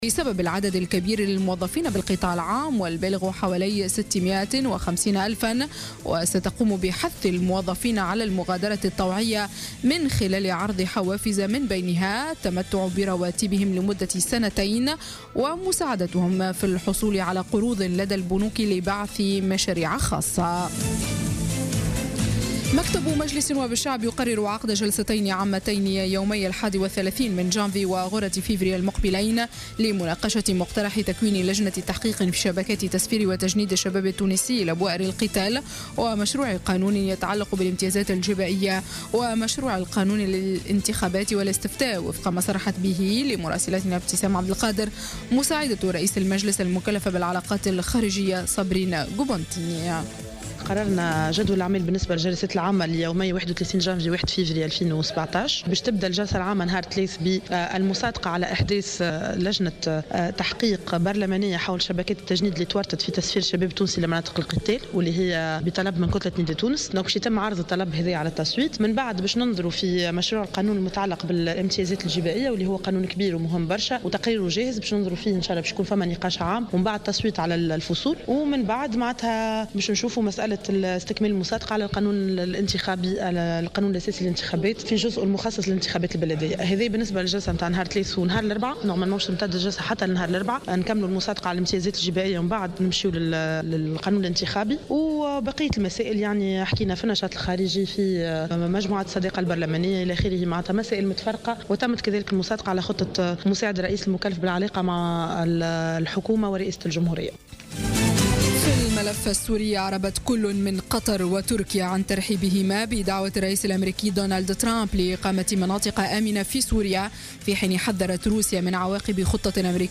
نشرة أخبار منتصف الليل ليوم الجمعة 27 جانفي 2017